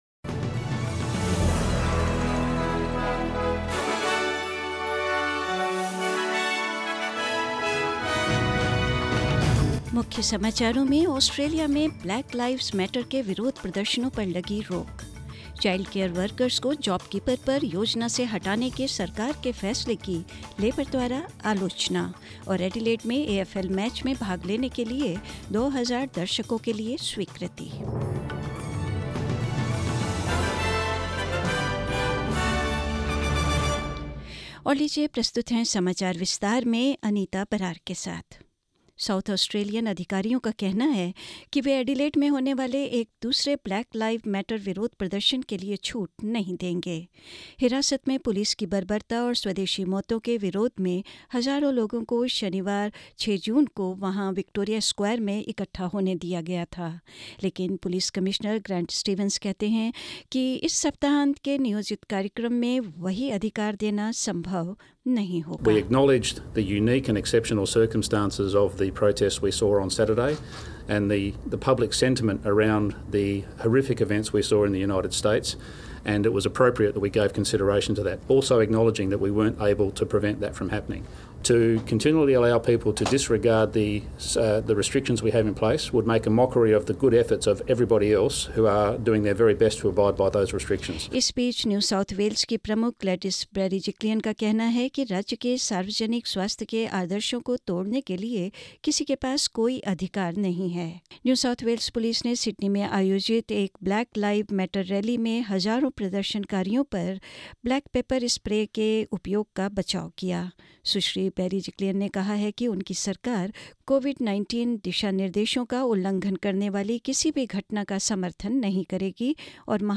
News in Hindi 9th June 2020